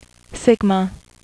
Sigma (SIG-muh)